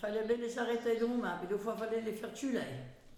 Localisation Saint-Christophe-du-Ligneron
Catégorie Locution